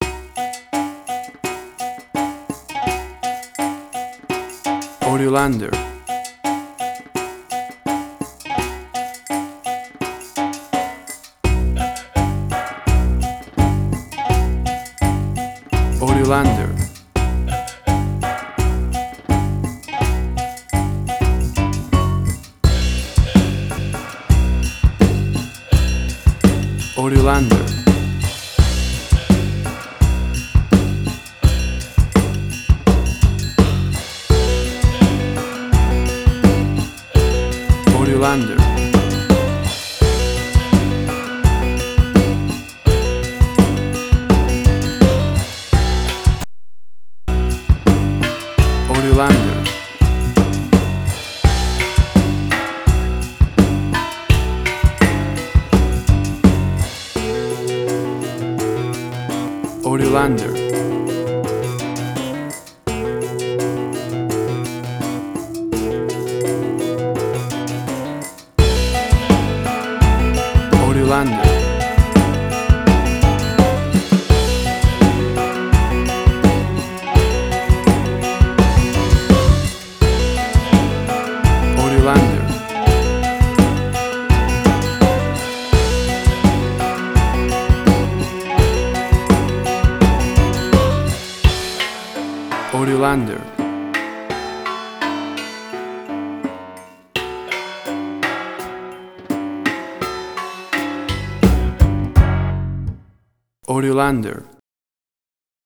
Strange weird, Quirky
Tempo (BPM): 85